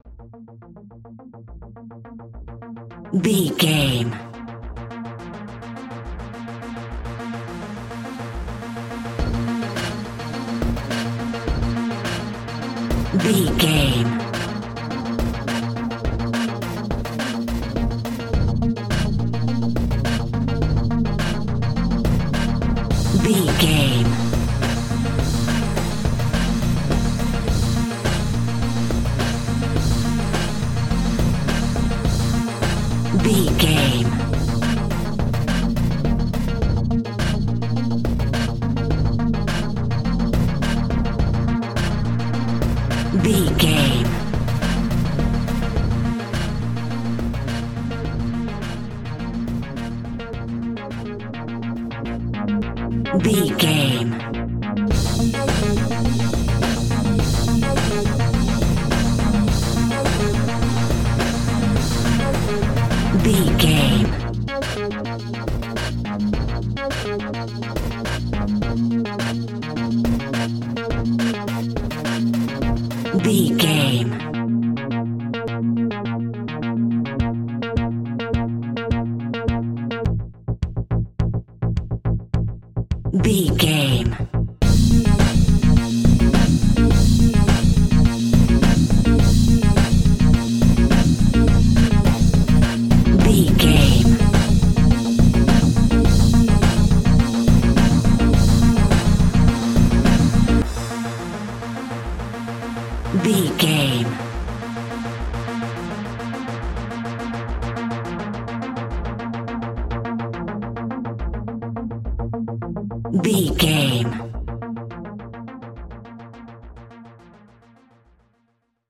Aeolian/Minor
B♭
electronic
techno
trance
industrial
synth lead
synth bass